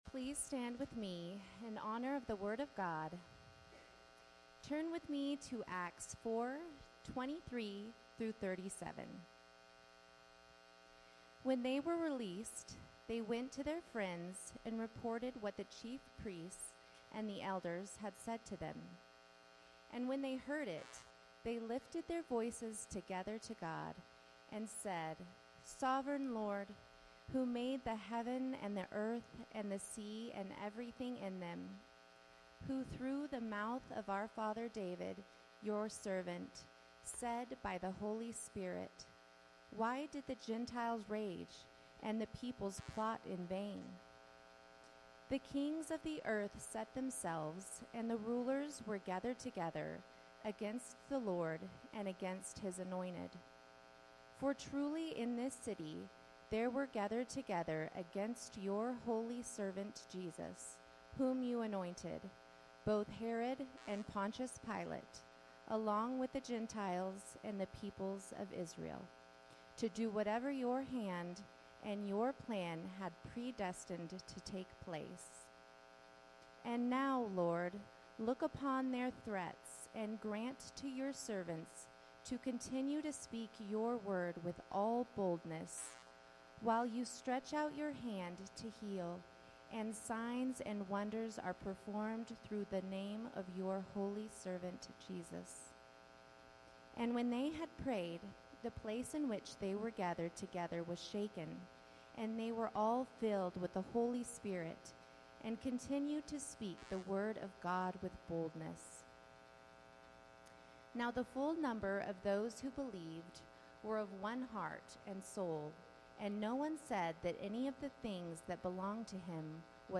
teaching through the book of 1 Peter in a sermon series called "Exiles"